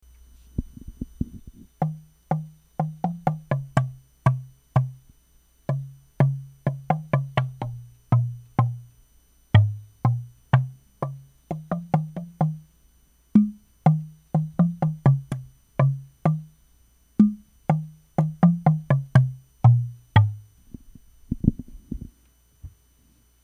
キャップをはずした空のペットボトルには、指のはらでボトルをたたくといい音がするものが結構あります。
バチでたたくときは、ボトルに古靴下の足首の部分をはめて、その上をたたくと衝撃音が和らぎます。